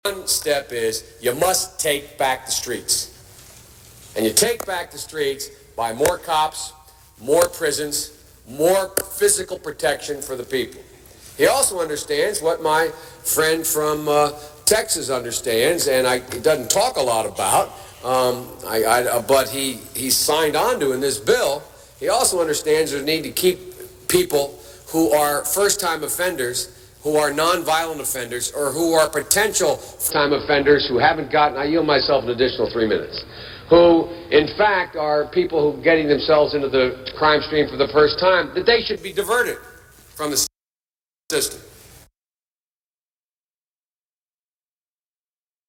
JOE BIDEN in his own words, Please Listen!!!! Lesser of two evils?